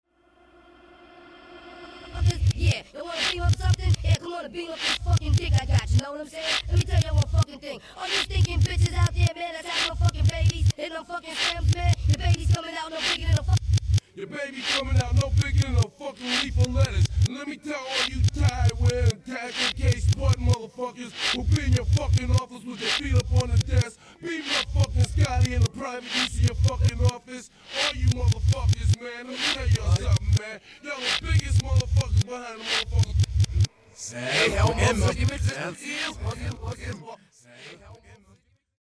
Anybody ever wonder what the hell was being said in reverse